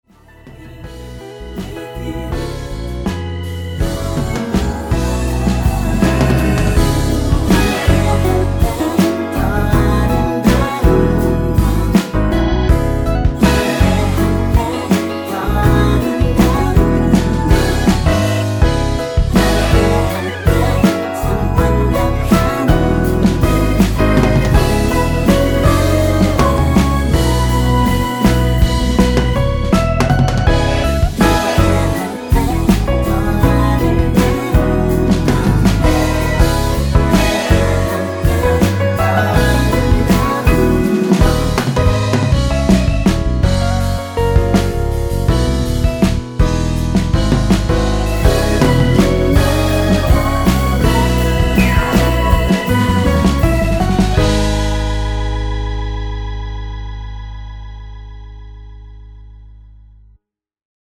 라이브 하시기 좋게 노래 끝나고 바로 끝나게 4분 36초로 편곡 하였습니다.(미리듣기 참조)
원키에서(+3)올린 코러스 포함된 MR입니다.
Eb
앞부분30초, 뒷부분30초씩 편집해서 올려 드리고 있습니다.